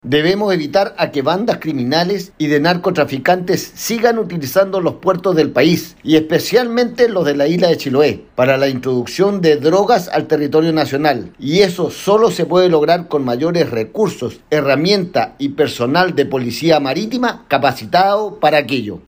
Al respecto el legislador señaló: